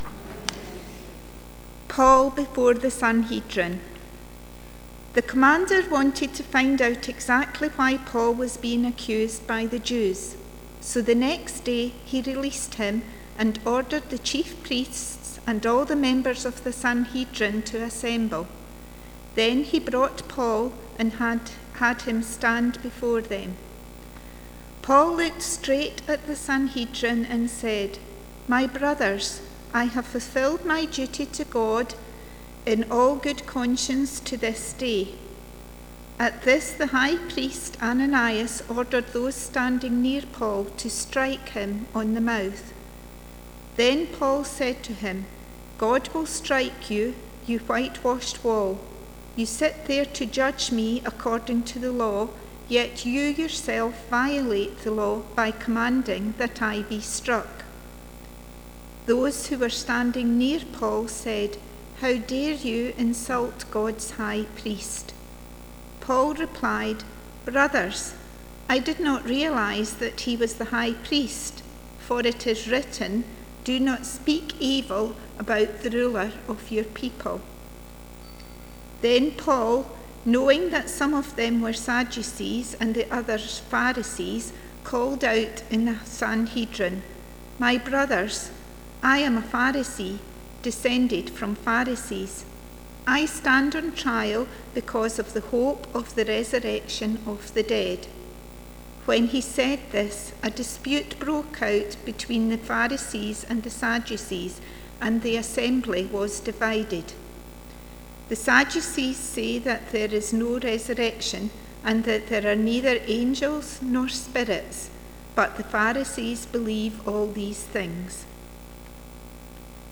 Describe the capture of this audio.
Acts Passage: Acts 22:30 - 23:11 Service Type: Sunday Morning « Paul the Roman citizen Paul’s adventures